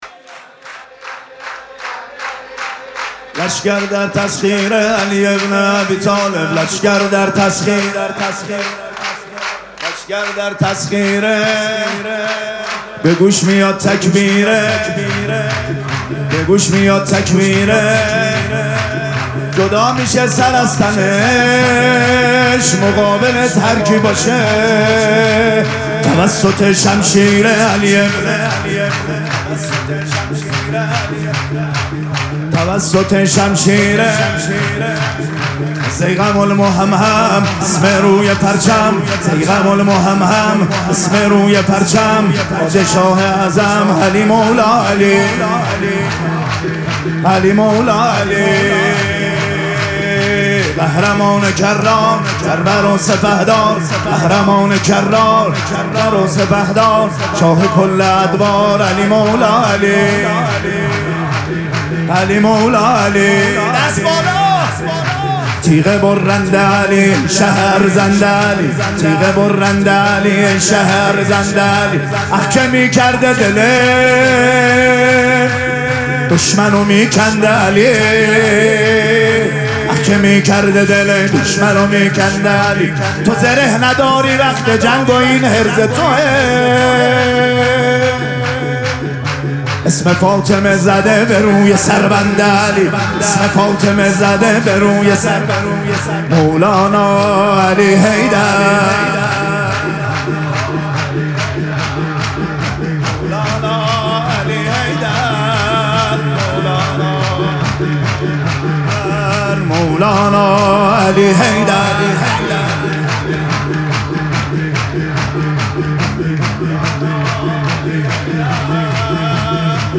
جشن ولادت حضرت رسول اکرم و امام صادق علیهماالسلام
حسینیه ریحانه الحسین سلام الله علیها
شور